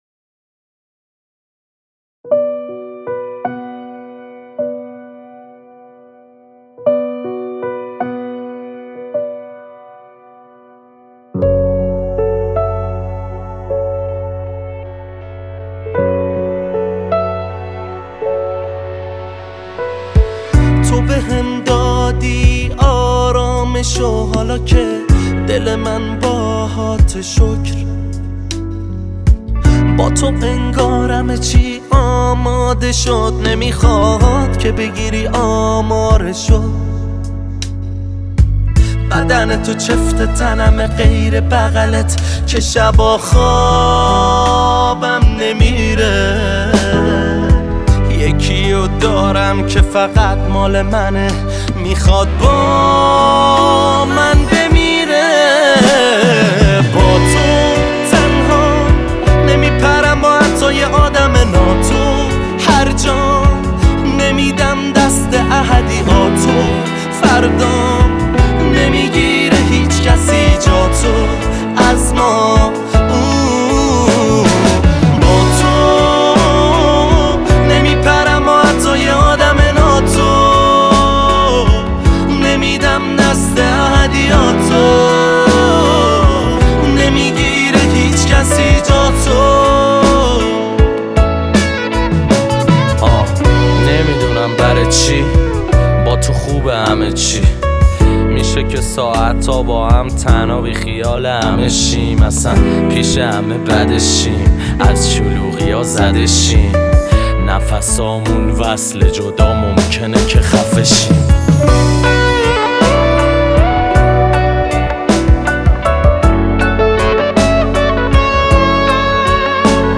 آراَندبی